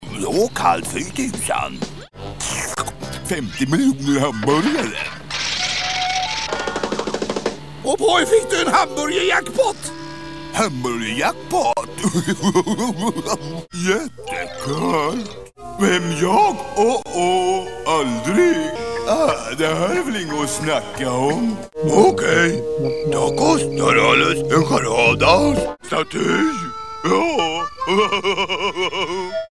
Jag såg att jag faktiskt hade lagt upp ljudklipp på Scooby-Doos olika röster för ett par år sedan, och de filerna fanns kvar på servern.